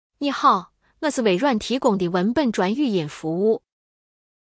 Edge-TTS是微软提供的在线文本转自然语音，支持多种语言和声音，转换速度快，语音自然无机械感。
同一段文字分别使用这几种声音转成语音文件对比：
shaanxi-Xiaoni.wav